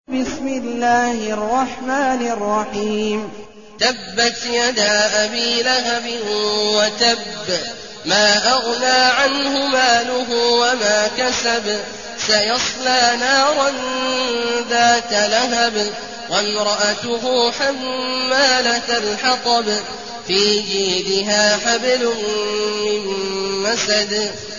المكان: المسجد النبوي الشيخ: فضيلة الشيخ عبدالله الجهني فضيلة الشيخ عبدالله الجهني المسد